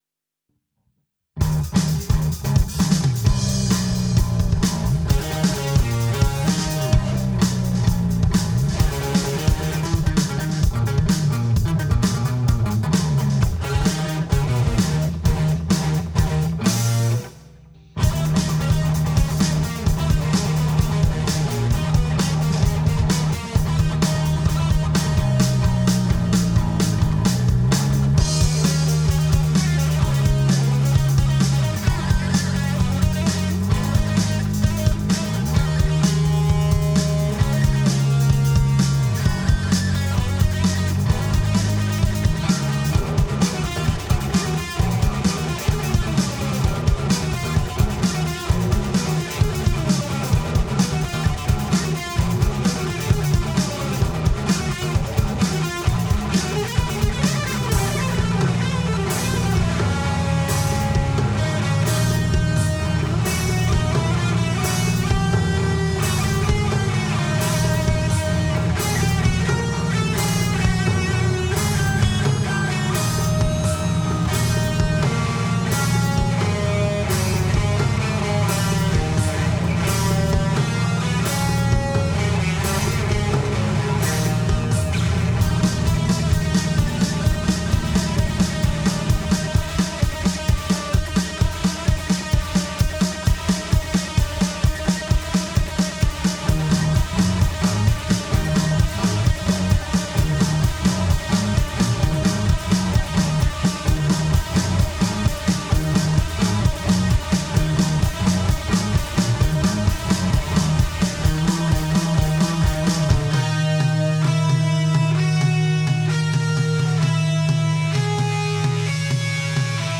Instrumental Guitar Rock (2018)
It really brings out the players on their instrumentation.